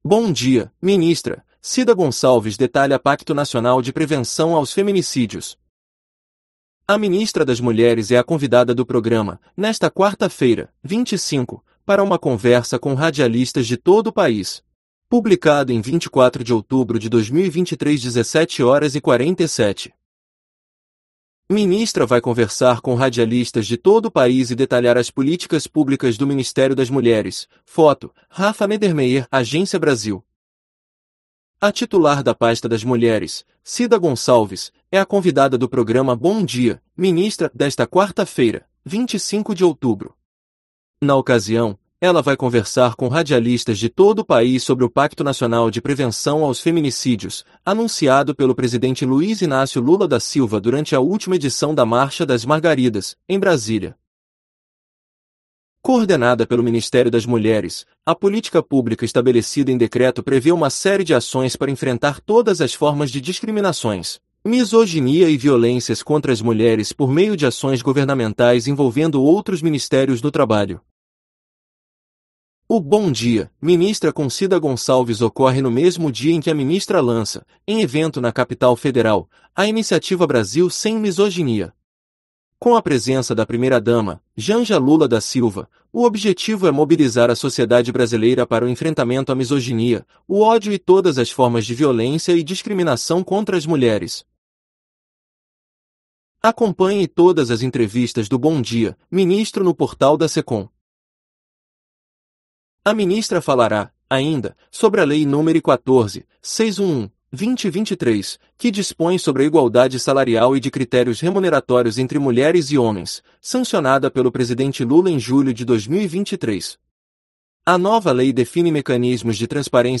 A ministra das Mulheres é a convidada do programa, nesta quarta-feira (25), para uma conversa com radialistas de todo o país